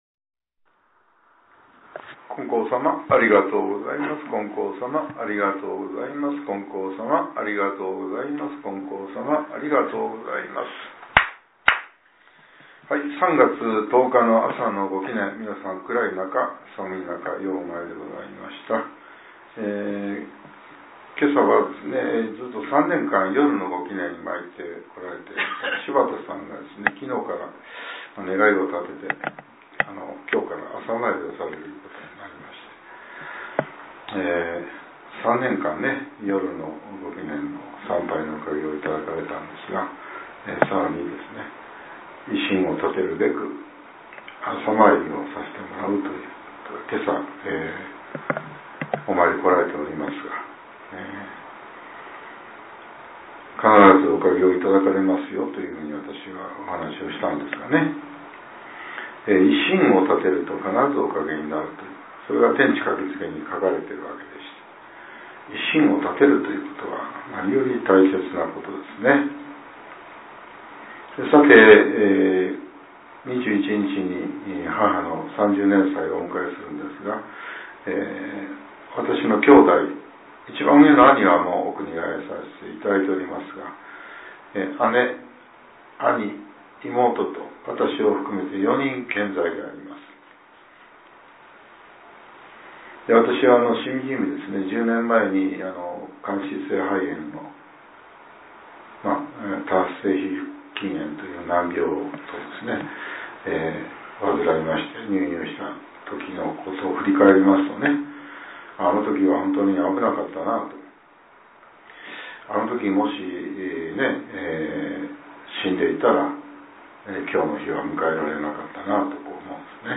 令和８年３月１０日（朝）のお話が、音声ブログとして更新させれています。